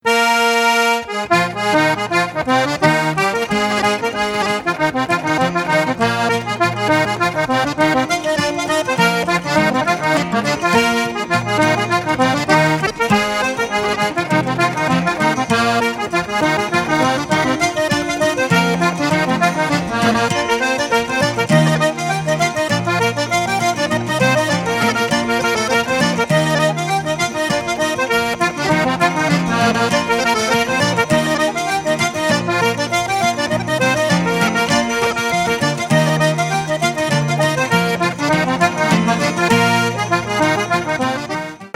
Straight down the line Irish traditional accordion music